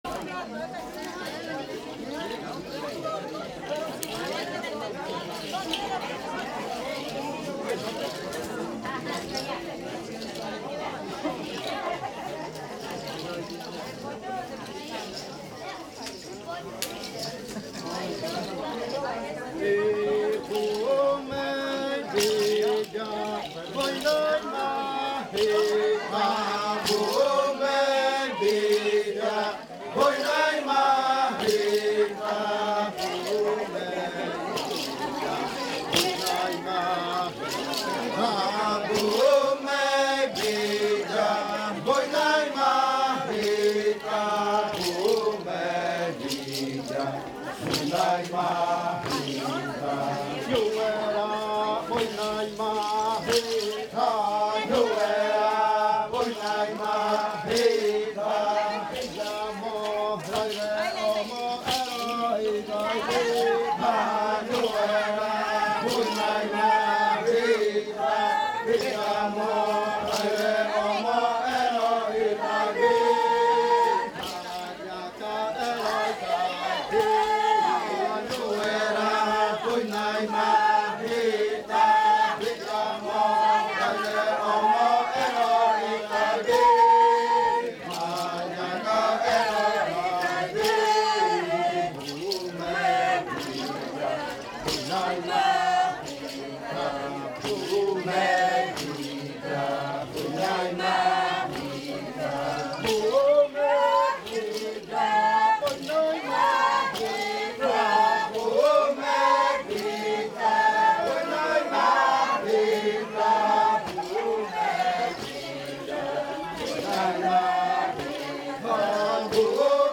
Canto de la variante muinakɨ
Leticia, Amazonas
con el grupo de cantores bailando en la Casa Hija Eetane. Este canto fue interpretado en el baile de clausura de la Cátedra de Lenguas "La lengua es espíritu" de la UNAL sede Amazonia.
with the group of singers dancing at Casa Hija Eetane.